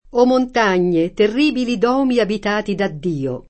dU0mo] s. m. («chiesa») — pop. o poet. domo [d0mo]: Migliore del Clero Che bazzica in Domo [mil’l’1re del kl$ro ke bb#ZZika in d0mo] (Giusti); O Montagne, terribili dòmi abitati da Dio [
o mont#n’n’e, terr&bili d0mi abit#ti da dd&o] (D’Annunzio) — sim. i top. Duomo (Lomb.) e Domo (Marche, Lazio, ecc.) — cfr. Domodossola